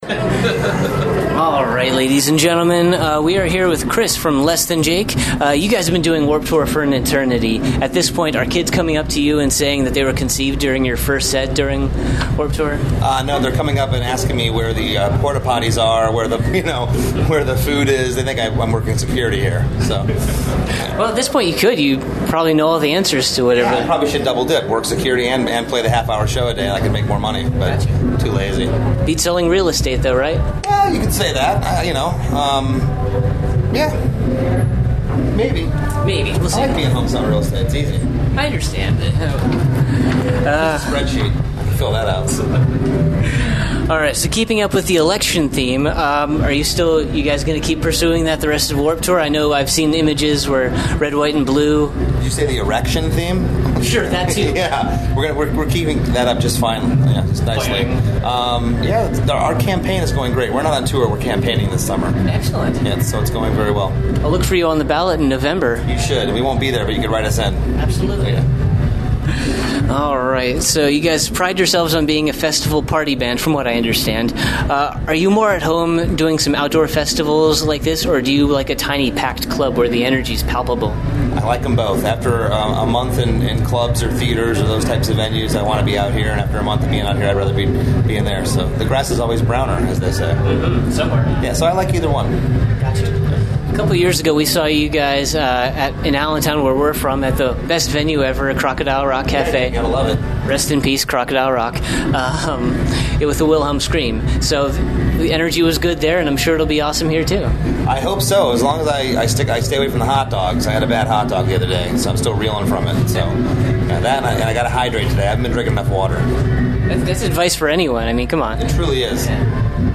Exclusive Interview: Less Than Jake